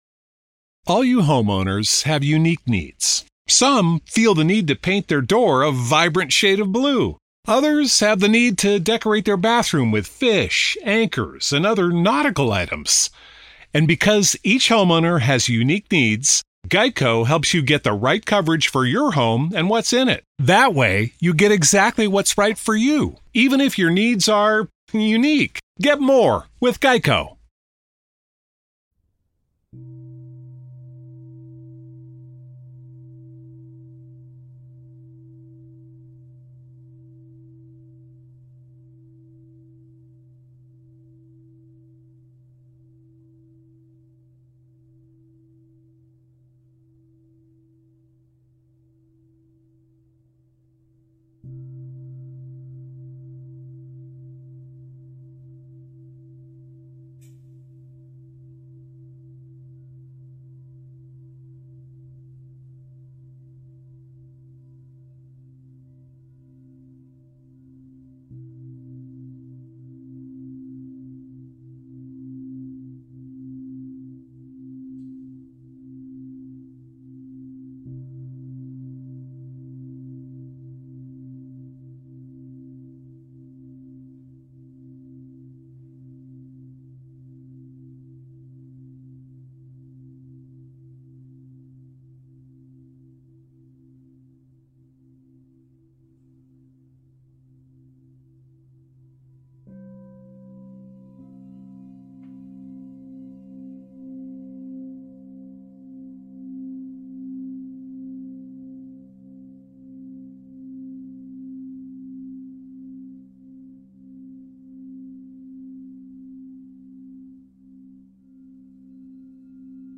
Hypnosis and relaxation ｜Sound therapy - The summer singing bowl can calm the mind and brain. Suitable for those who are overexerting themselves or are too excited to control themselves